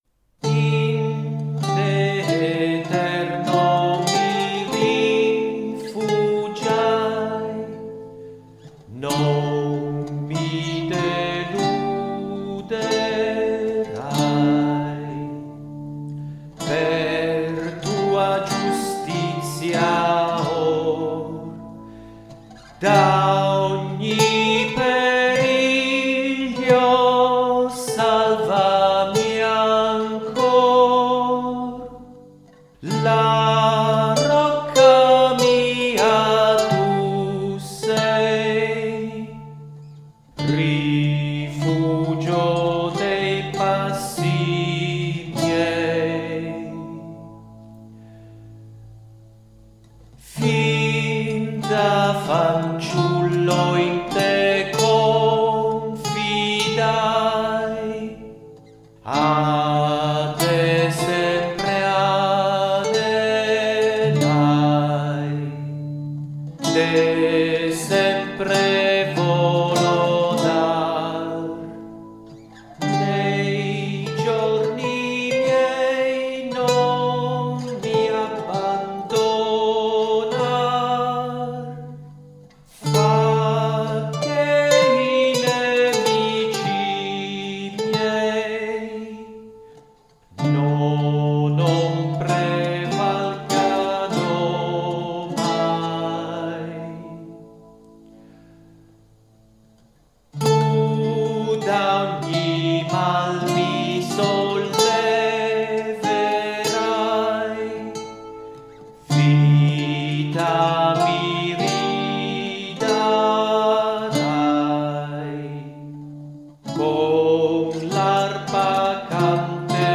Salmi cantati
con accompagnamento della chitarra